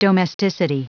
Prononciation du mot domesticity en anglais (fichier audio)
Prononciation du mot : domesticity